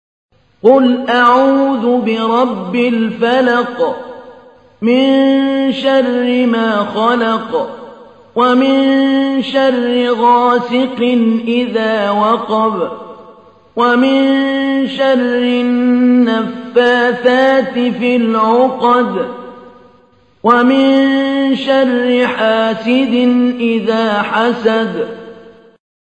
تحميل : 113. سورة الفلق / القارئ محمود علي البنا / القرآن الكريم / موقع يا حسين